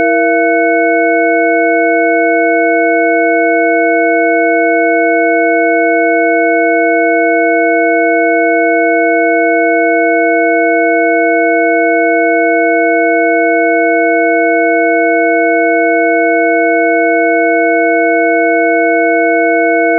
Sie sind 20 Sekunden lang, enthalten 6 bzw. 8 Kanäle mit 6 bzw. 8 verschiedenen Frequenzen von 330 Hz bis 2200 bzw. 4700 Hz mit 48 kHz Sample rate:
Sin6Ch48kHz_E6.WAV